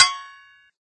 wheel_stop.ogg